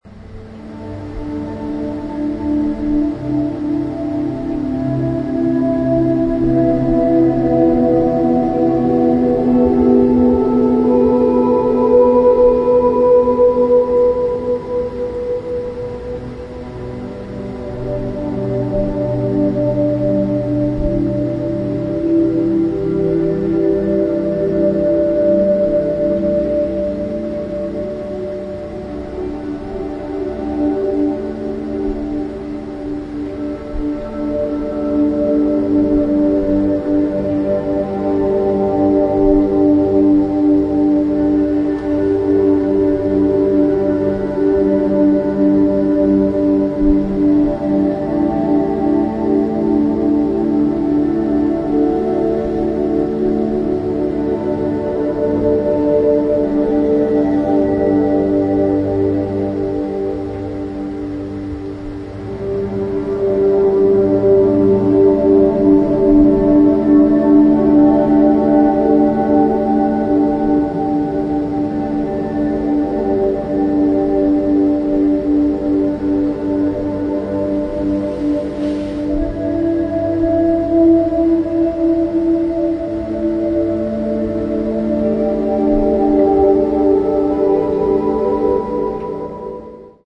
沈黙の中に響く微かなノイズ。
ギターの透明な響きは美しい終わりの光景へと溶けていきます。